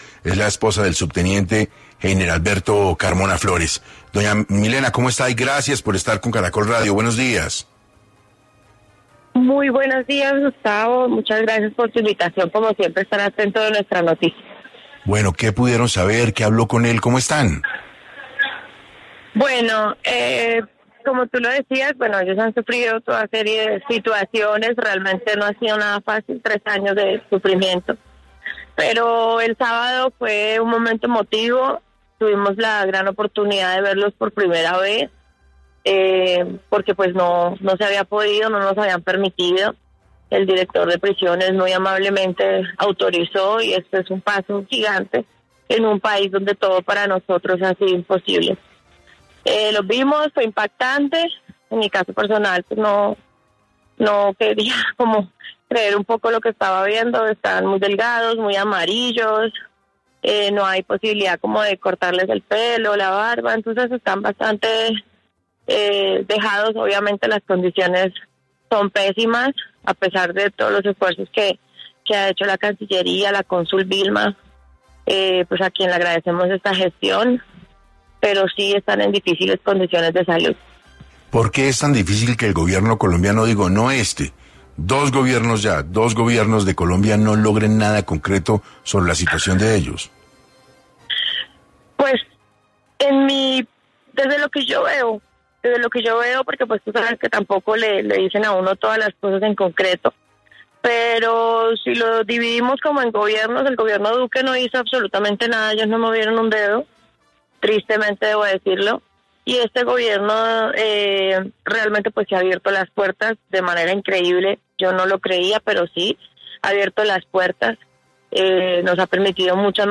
En el programa 6AM Hoy por Hoy de Caracol Radio, la esposa del subteniente habló de bajo qué condiciones puede ver a su esposo y solicitó su libertad.